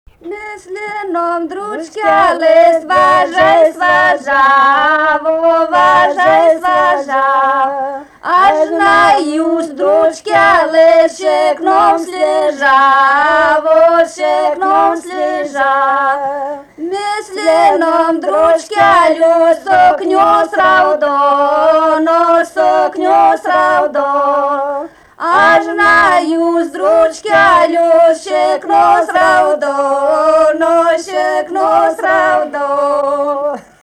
vokalinis
3 balsai